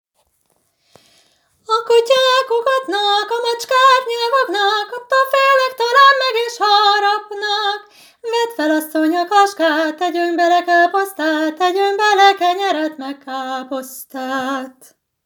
Az ének-zenei nevelés óvodai módszertanának e területén különösen nagy a hiányosság, s ezt az elmaradást igyekszik pótolni a tanulmány egy autentikus vokális cigány népzenei anyag gyűjteményével.